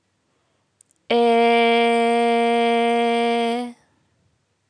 Vocale în format .wav - Vorbitorul #21